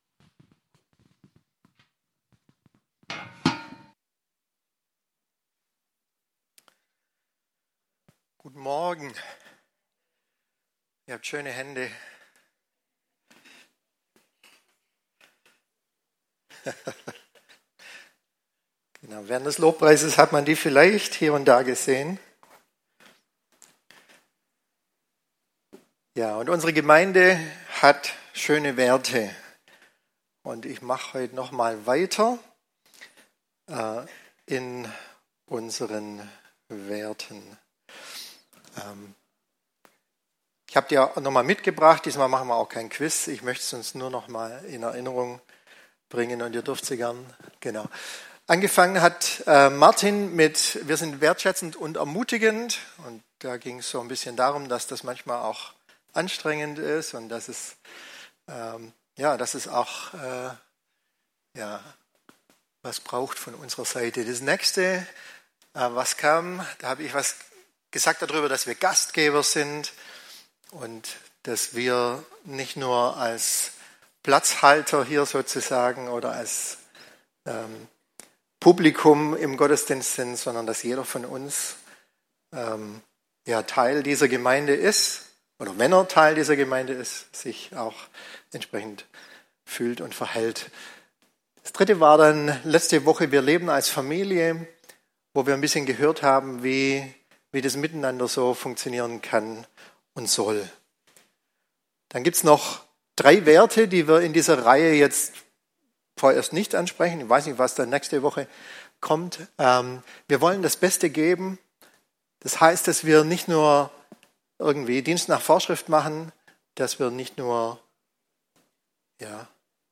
Predigt-Details - FCG Ecclesia Laupheim